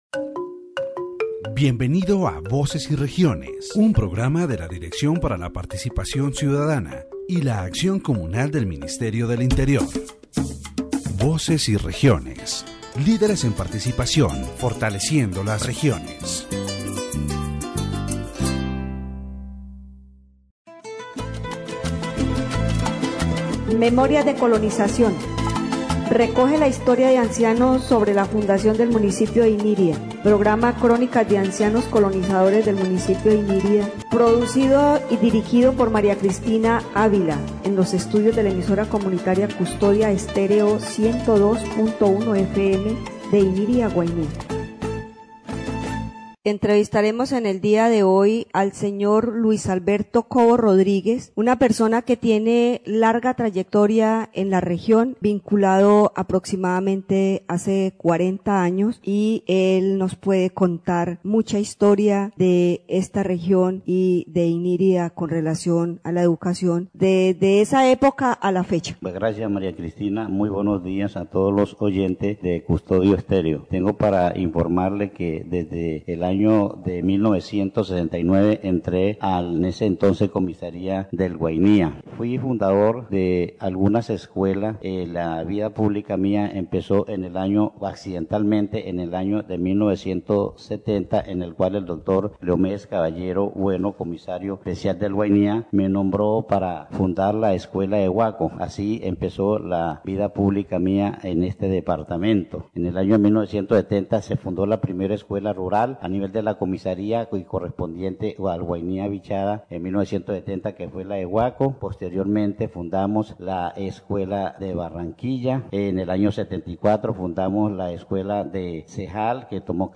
In this edition of the Voices and Regions program, the story of an elderly person is shared, recounting their arrival in the municipality of Inírida, Colombia. During the interview, they describe how they started working from a young age in various trades, overcoming challenges and contributing to the region's growth.